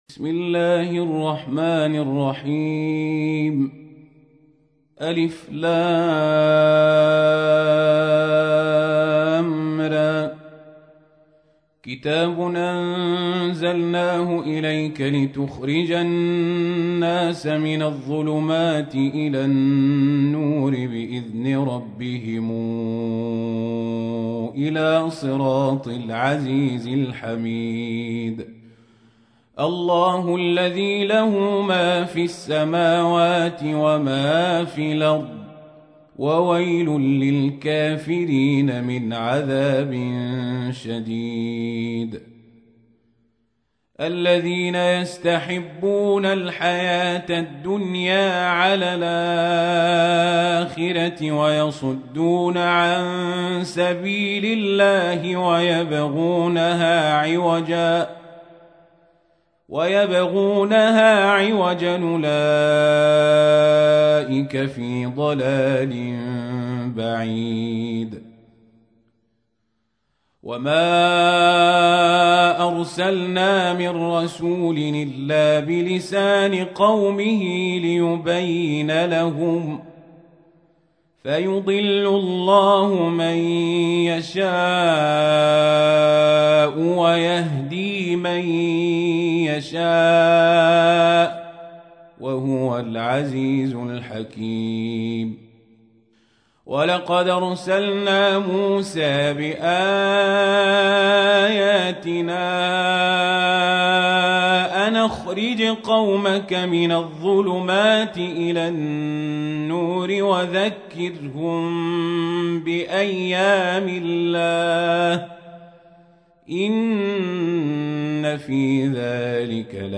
تحميل : 14. سورة إبراهيم / القارئ القزابري / القرآن الكريم / موقع يا حسين